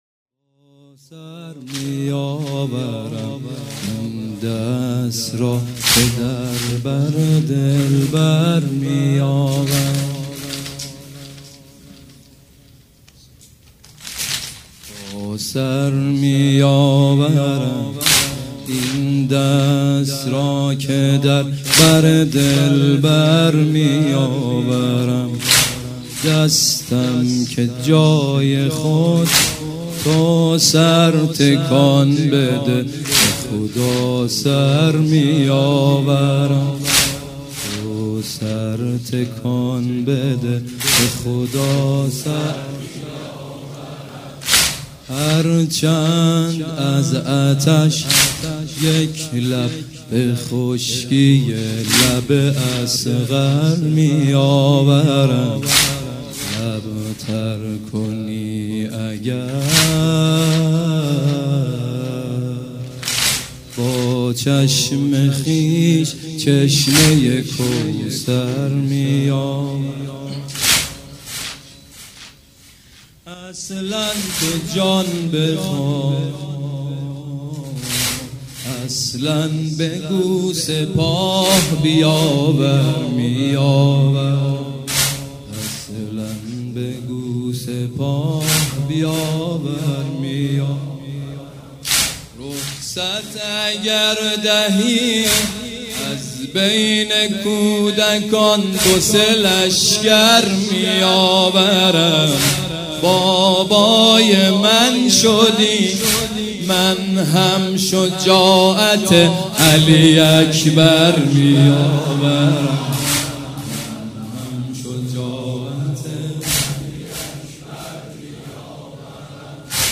سیدمجید بنی‌فاطمه مداح
مناسبت : شب پنجم محرم